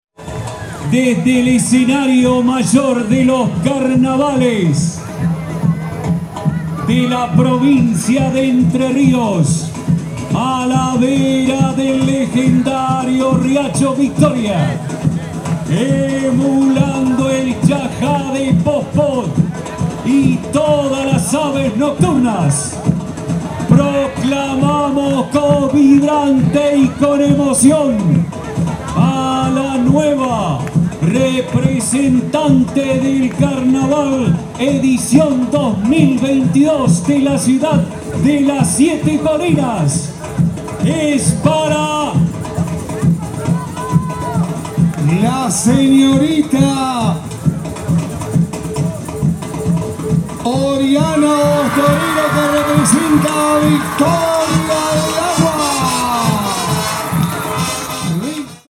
Se realizó anoche la segunda noche de Carnaval, oportunidad donde se llevó a cabo la elección de las Representantes del Carnaval de Victoria.
Momento de la elección de la Representante del Carnaval de Victoria